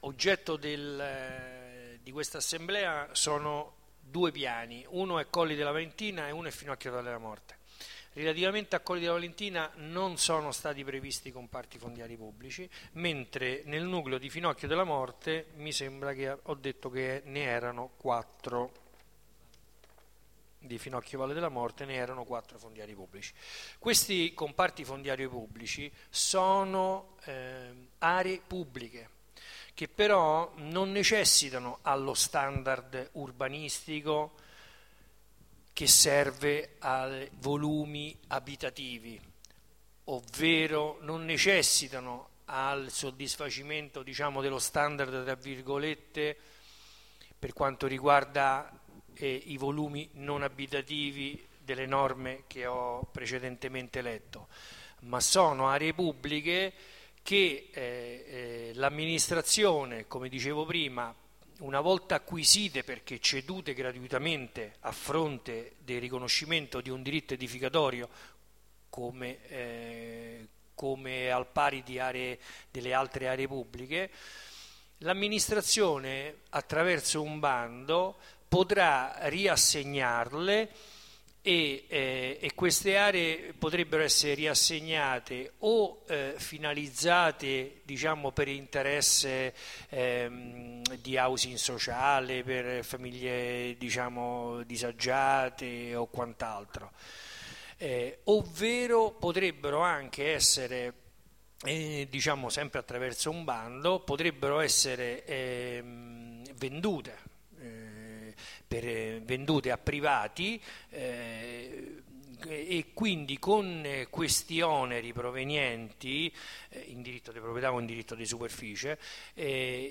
Assemblea
Registrazione integrale dell'incontro svoltosi il 6 dicembre 2012 presso la Sala Consiliare del Municipio Roma VIII